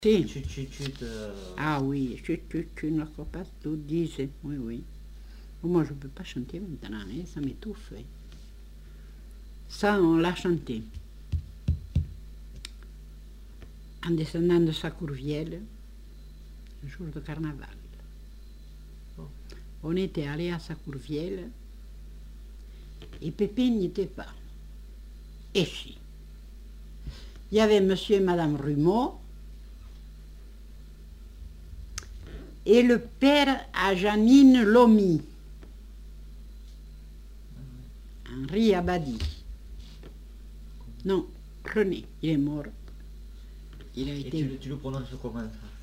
Aire culturelle : Comminges
Lieu : Montauban-de-Luchon
Genre : récit de vie